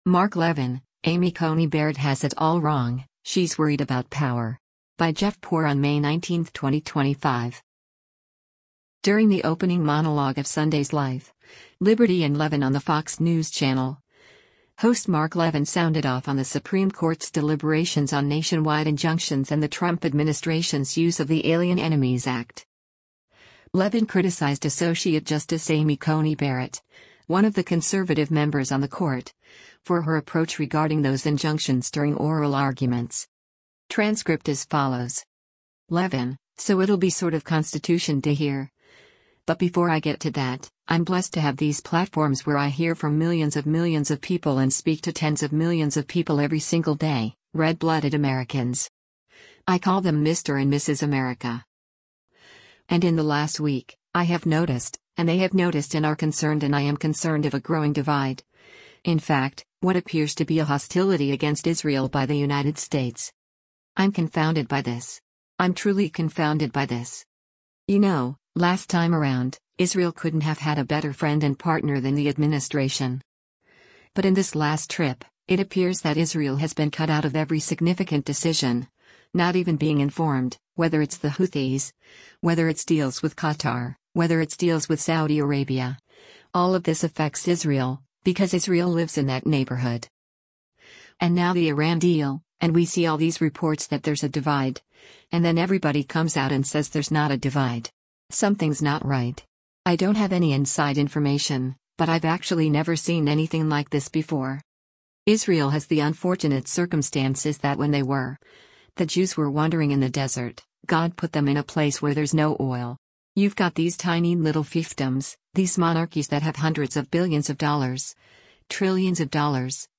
During the opening monologue of Sunday’s “Life, Liberty & Levin” on the Fox News Channel, host Mark Levin sounded off on the Supreme Court’s deliberations on nationwide injunctions and the Trump administration’s use of the Alien Enemies Act.